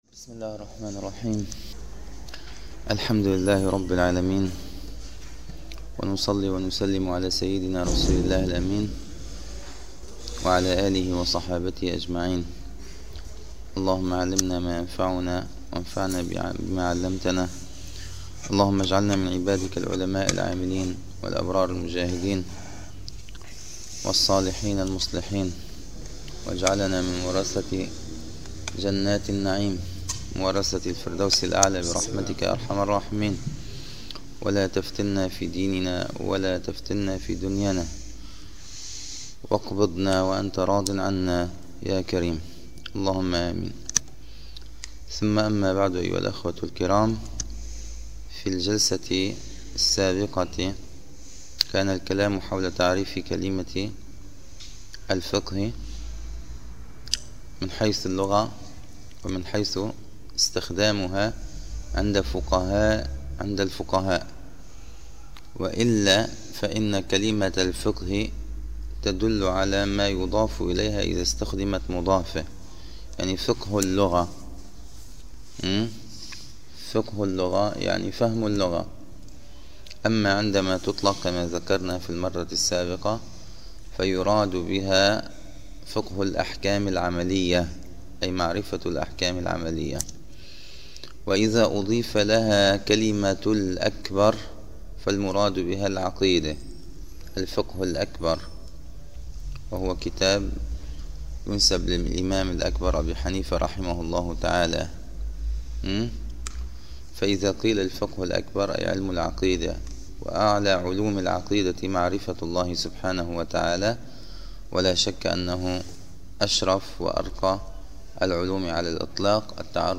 دروس فقه
في مسجد القلمون الغربي